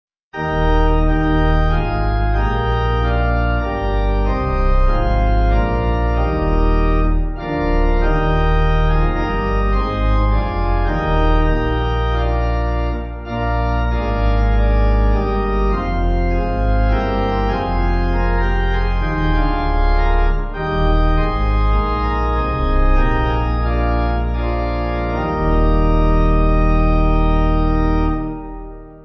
Organ
(CM)   3/Eb